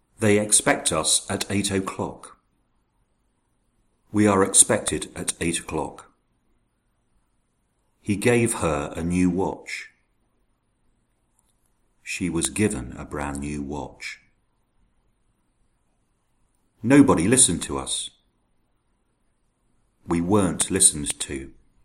Alle opnames zijn ingesproken door native speakers.